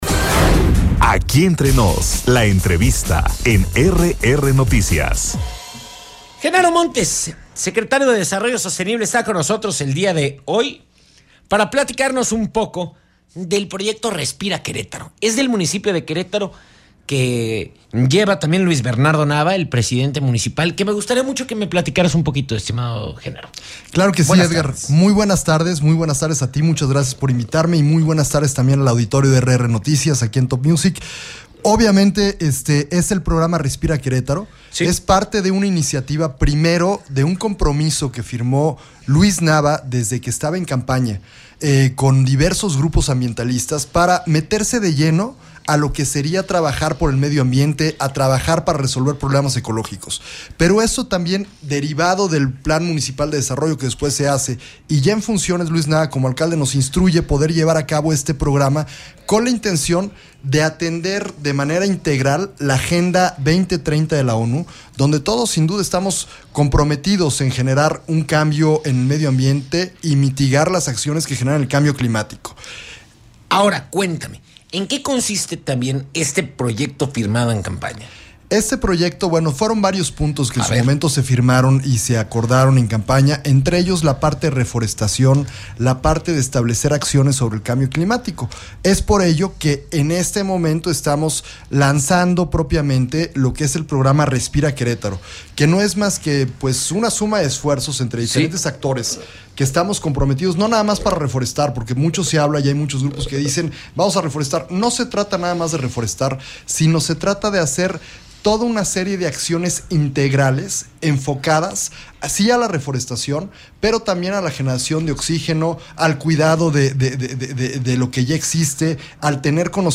ENTREVISTA-GENARO.mp3